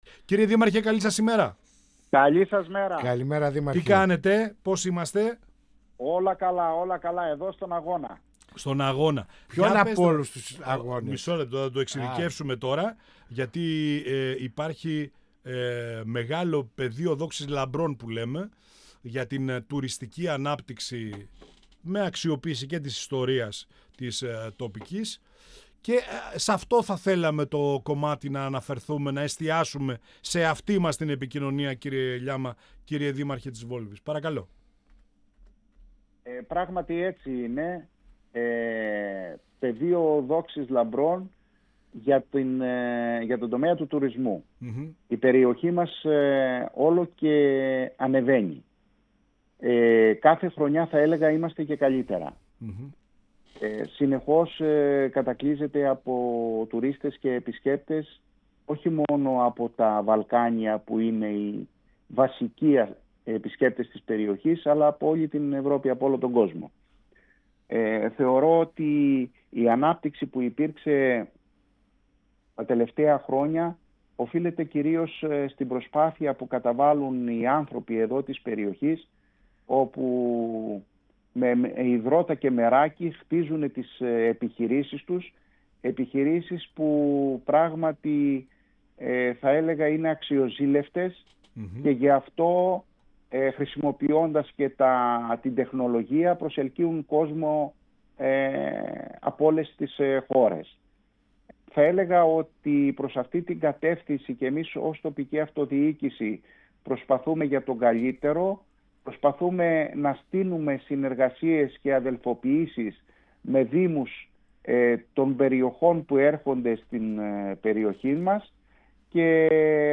Ο Δήμαρχος Βόλβης Διαμαντής Λιάμας, στον 102FM της ΕΡΤ3 | «Πανόραμα Επικαιρότητας» | 25.04.2026